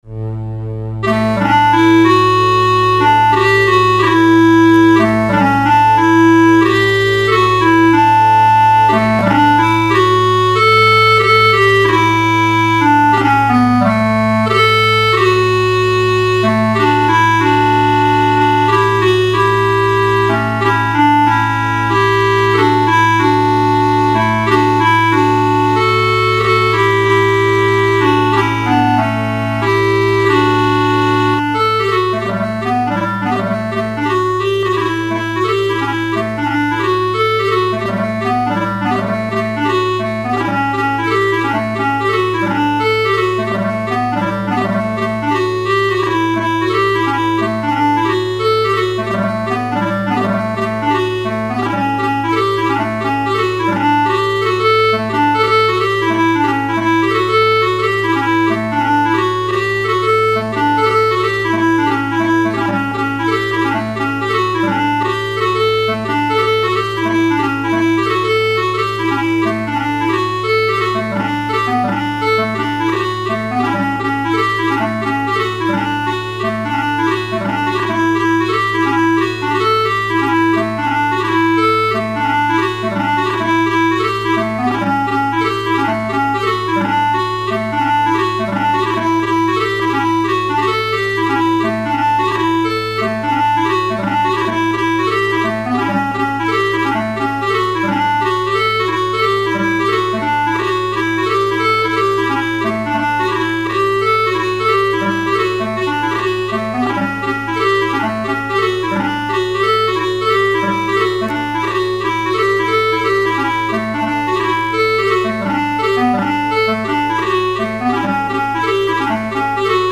Theme Tune